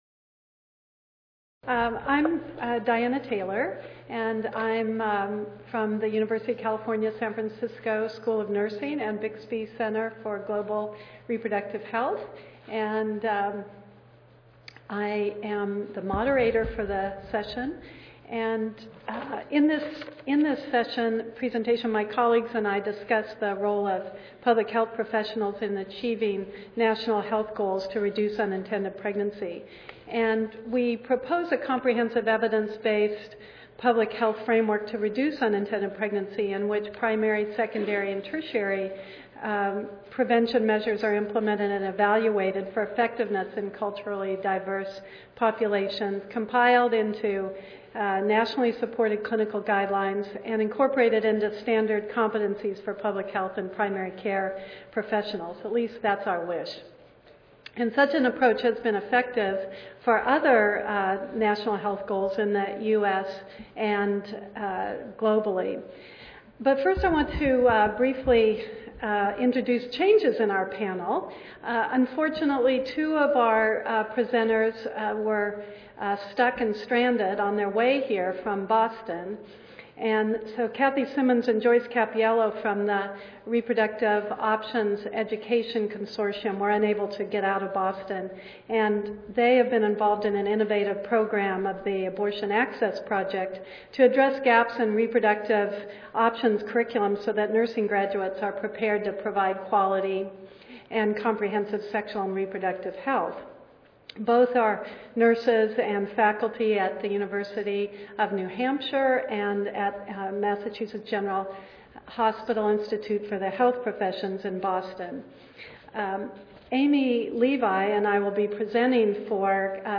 3163.0 New strategies for unintended pregnancy prevention Monday, October 29, 2012: 10:30 AM - 12:00 PM Panel Discussion For more than thirty years, the United States rate of unintended pregnancy has remained at 49% of all pregnancies. This panel will review factors that contribute to this high rate and present models for improvement.